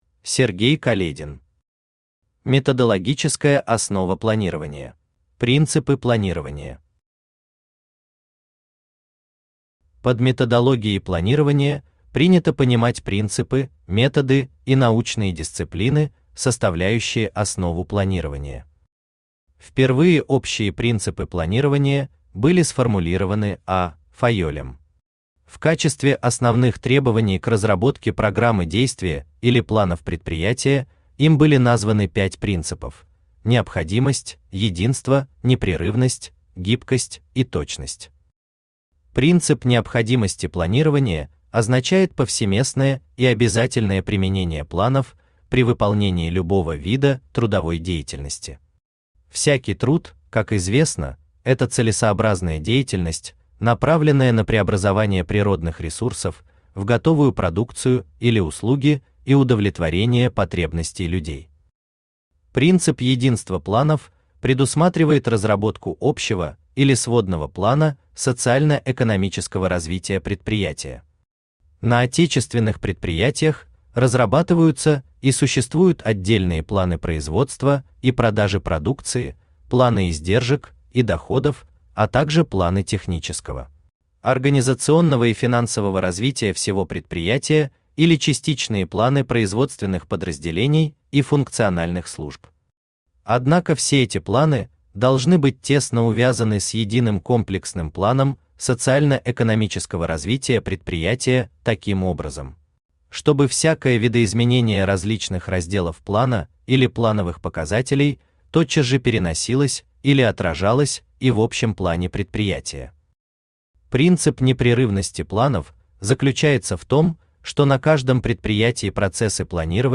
Аудиокнига Методологическая основа планирования | Библиотека аудиокниг
Aудиокнига Методологическая основа планирования Автор Сергей Каледин Читает аудиокнигу Авточтец ЛитРес.